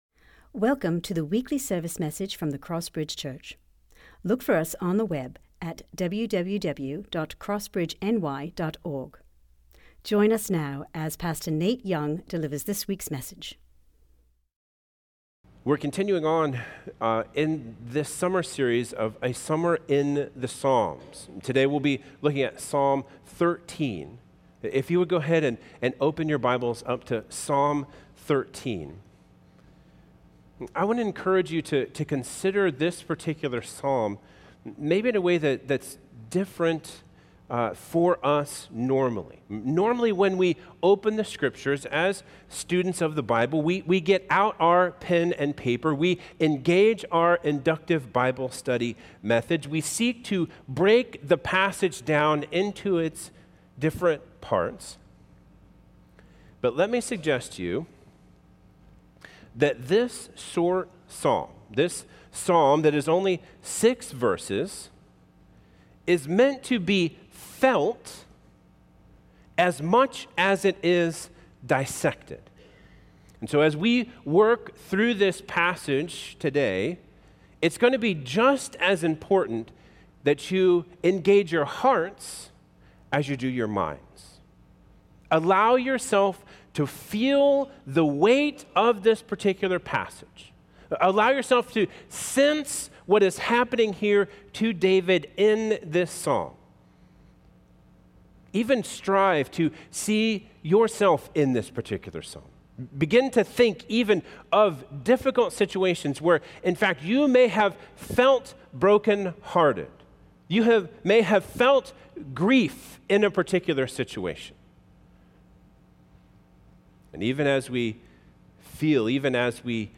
Sermons | CrossBridge Church